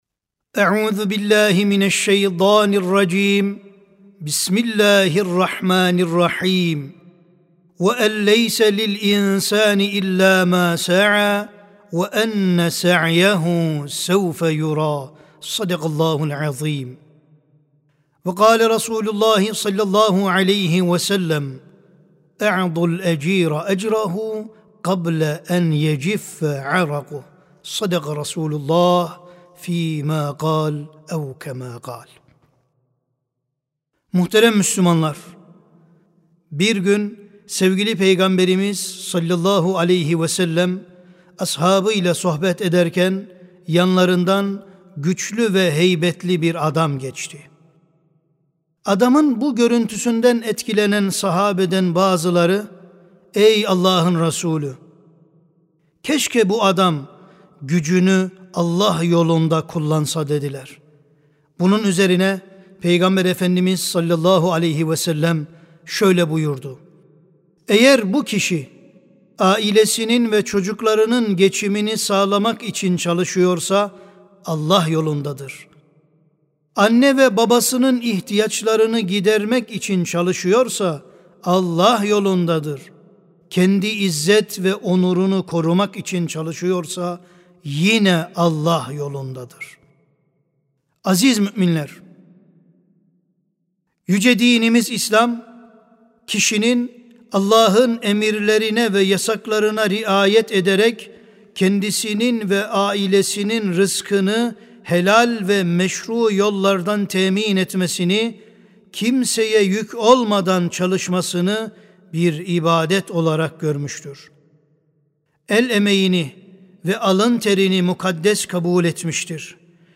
Sesli Hutbe (Alın Teri Mukaddestir).mp3